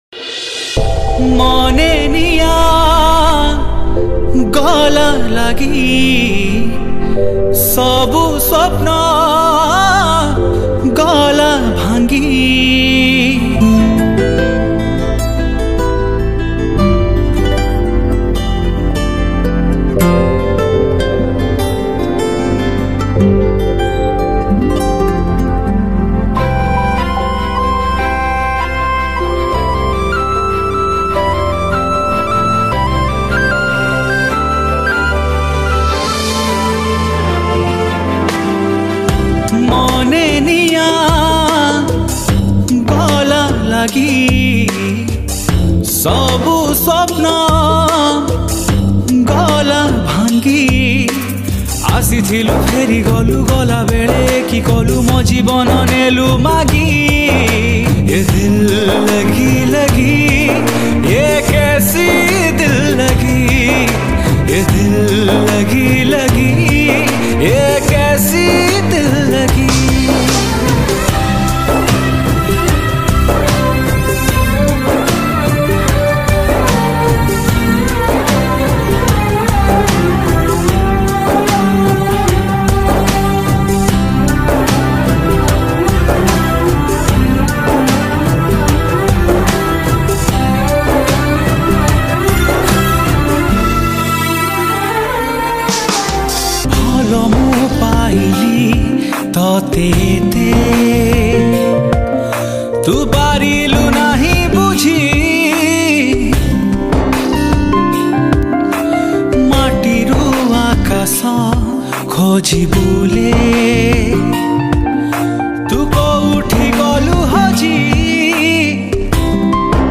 Emotional Sad Song